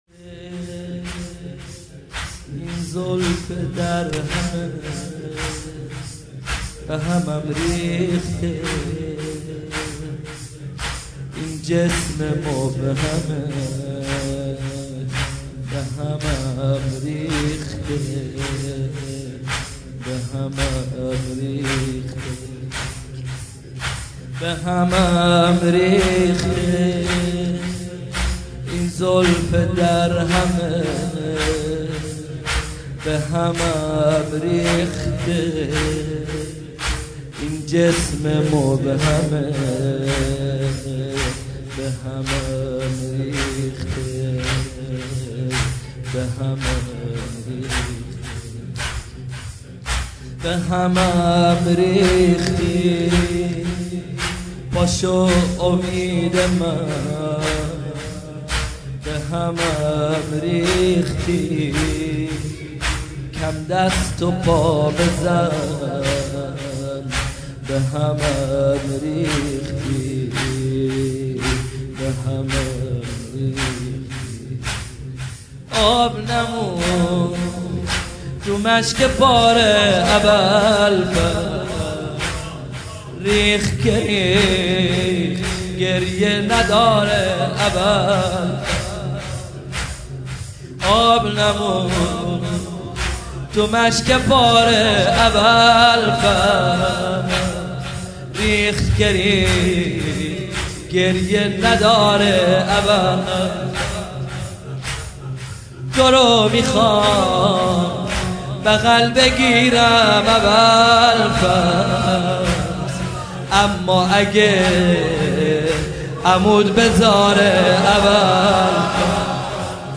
واحد| بهمم ریخته این زلف درهمت، بهمم ریخته این جسم مبهمت
مداحی
شب 9 محرم- سال 1441 هجری قمری | هیأت علی اکبر بحرین